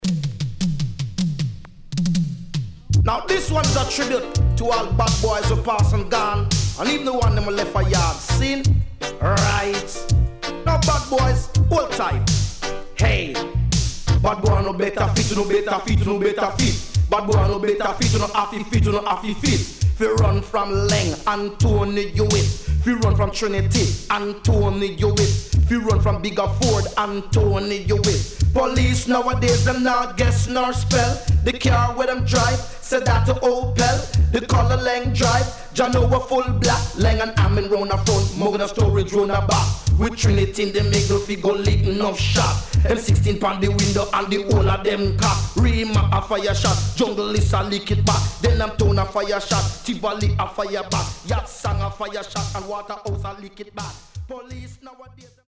HOME > DISCO45 [DANCEHALL]
SIDE A:少しチリノイズ入りますが良好です。